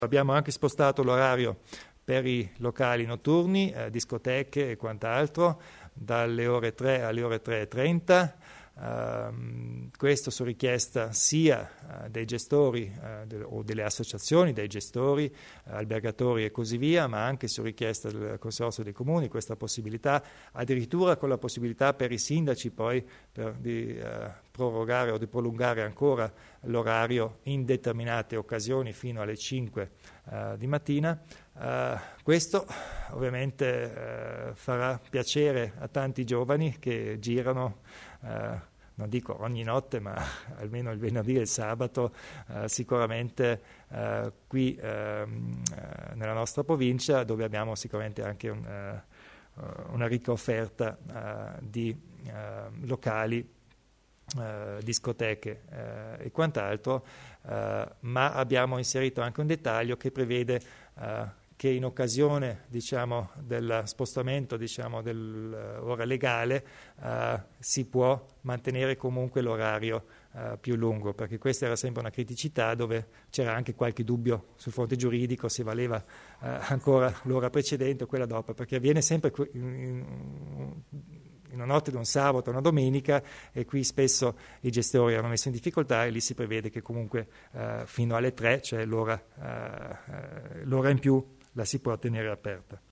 Il Presidente Kompatscher illustra i cambianti in materia di orario di apertura dei locali pubblici